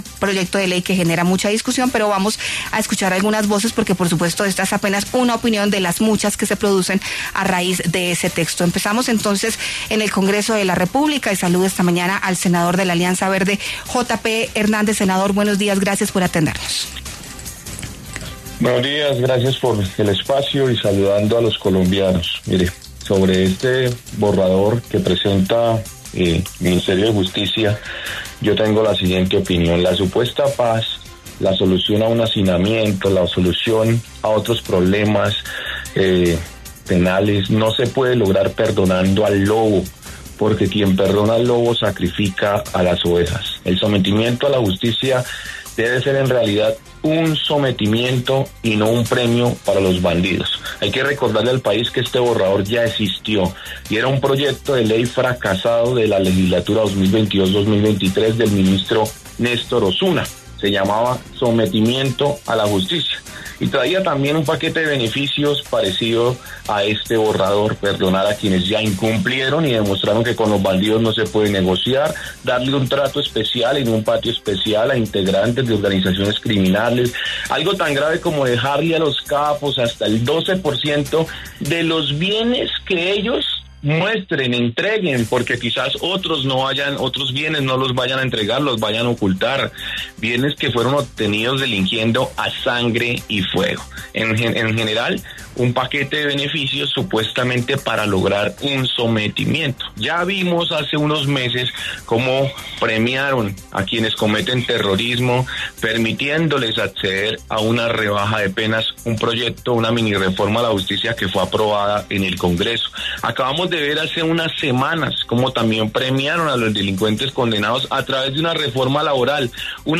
Los congresistas ‘JotaPe’ Hernández, de Alianza Verde, y Alirio Uribe, del Pacto Histórico, debatieron en La W sobre el borrador de proyecto del MinJusticia sobre la paz total.